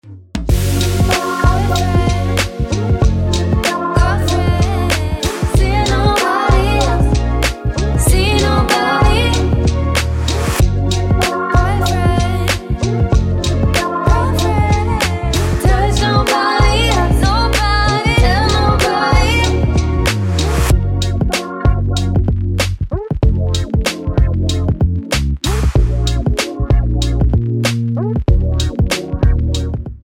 --> MP3 Demo abspielen...
Tonart:Bm mit Chor